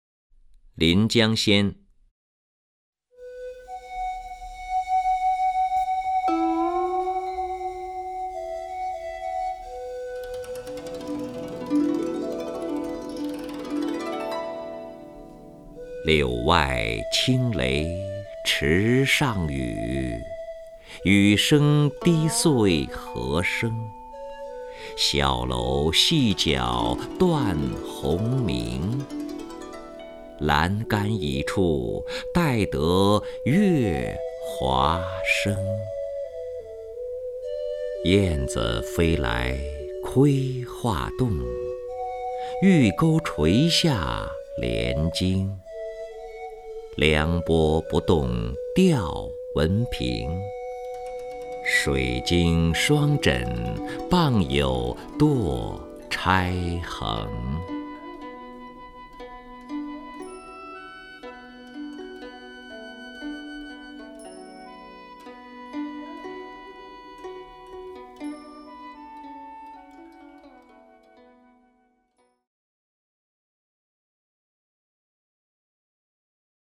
任志宏朗诵：《临江仙·柳外轻雷池上雨》(（北宋）欧阳修)
LinJiangXianLiuWaiQingLeiChiShangYu_OuYangXiu(RenZhiHong).mp3